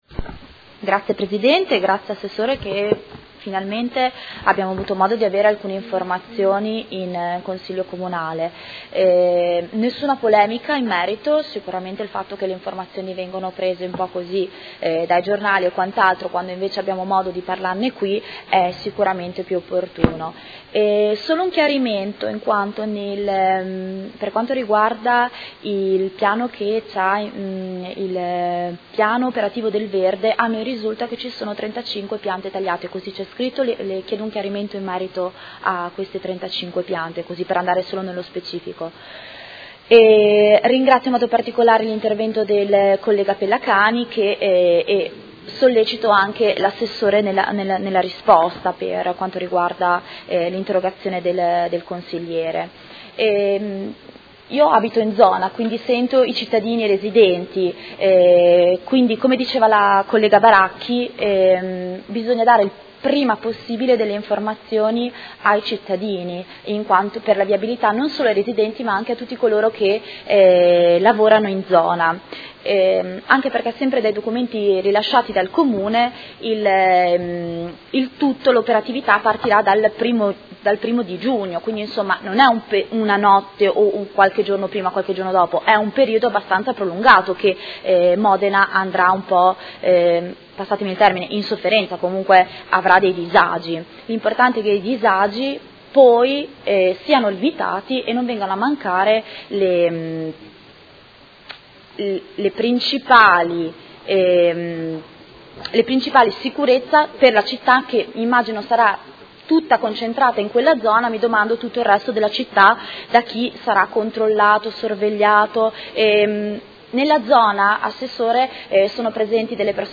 Seduta del 30/03/2017. Dibattito inerente le interrogazioni sul concerto di Vasco Rossi